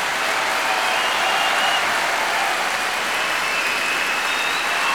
Crowd (1).wav